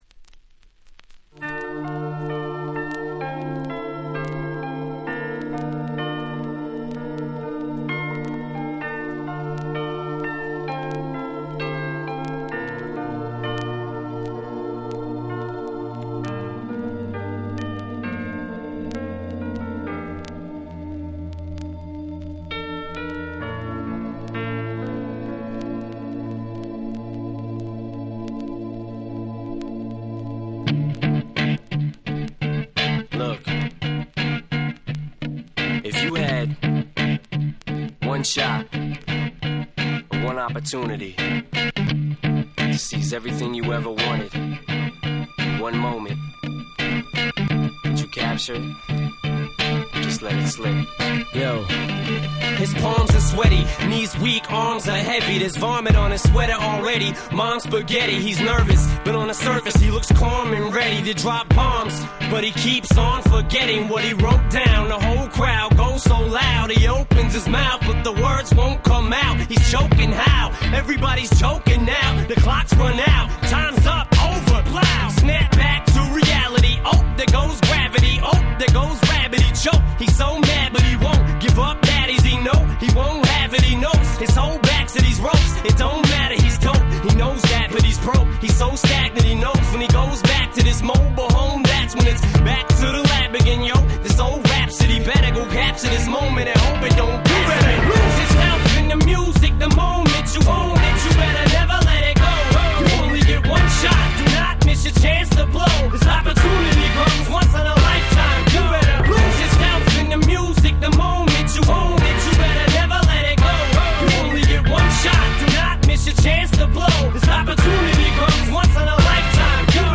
02' Super Hit Hip Hop !!